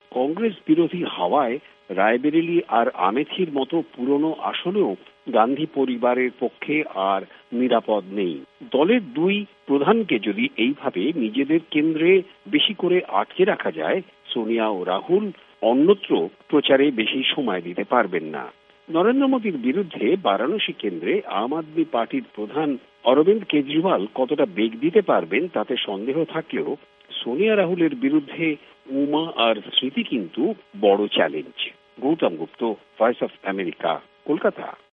ভয়েস অব আমেরিকার কোলকাতা সংবাদদাতাদের রিপোর্ট